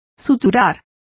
Complete with pronunciation of the translation of suturing.